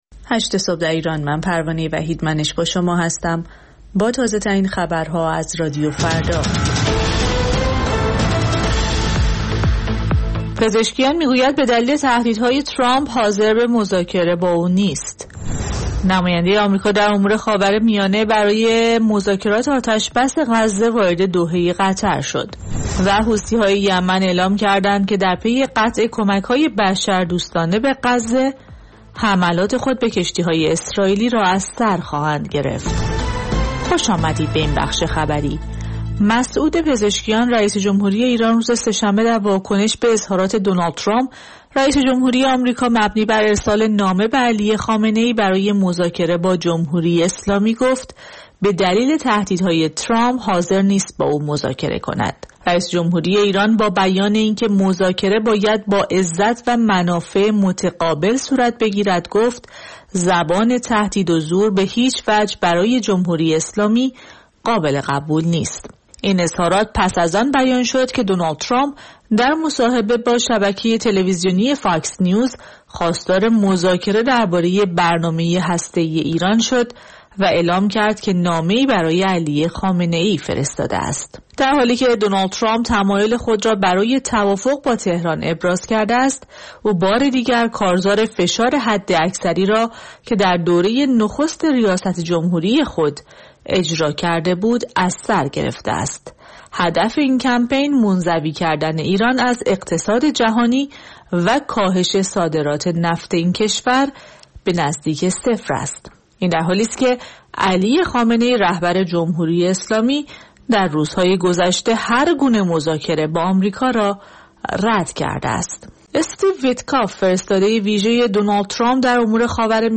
سرخط خبرها ۸:۰۰